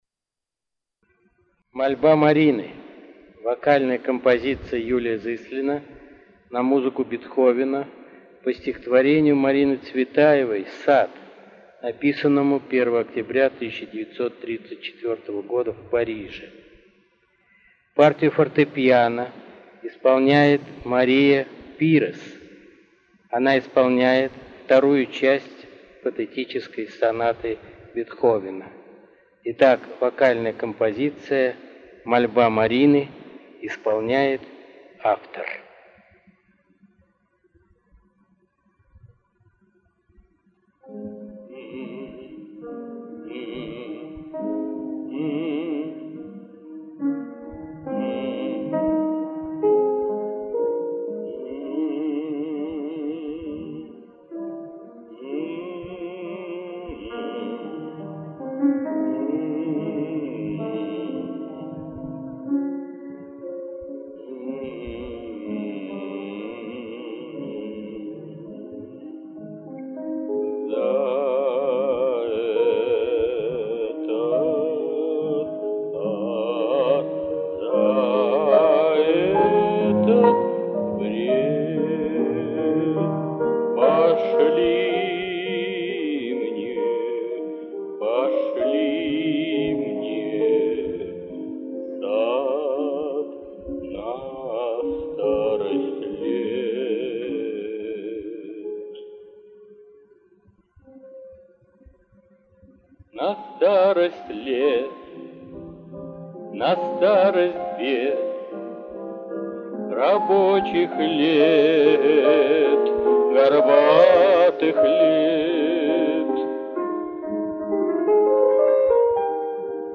Вокальная композиция
Авторская аудиозапись 2005 г. (под фонограмму фортепиано)
2015 г.  Авторское исполнение в тональности фа-минор